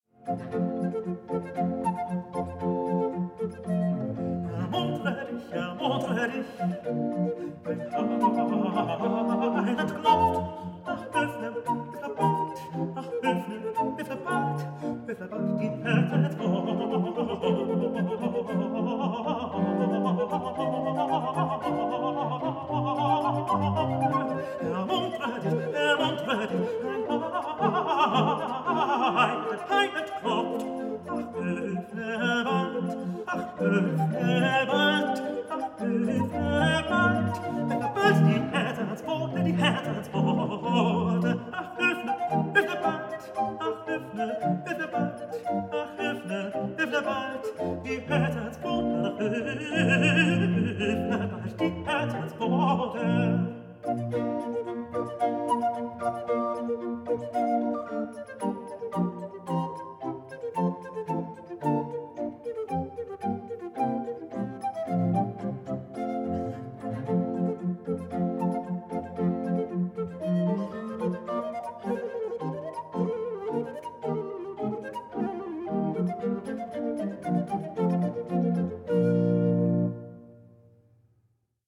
flute.
tenor
a sweet-toned and thoughtful soloist